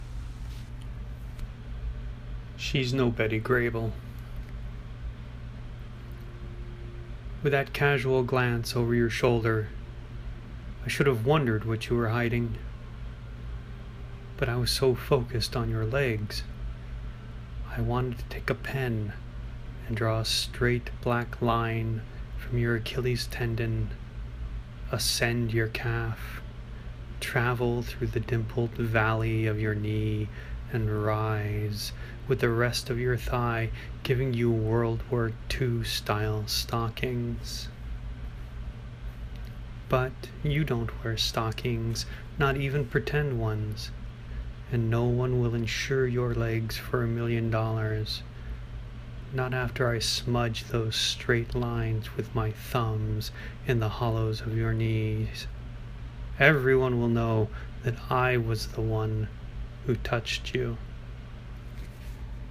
Tag: reading
My poem for Day 7 of the Tuple 30/30 project can be read here, but you can listen to it on this very page.